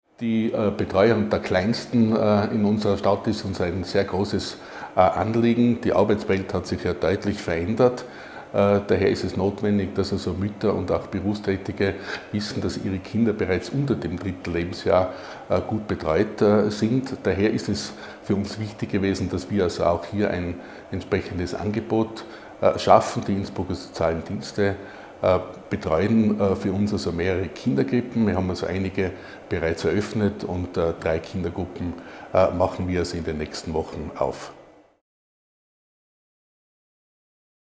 OT von Stadtrat Ernst Pechlaner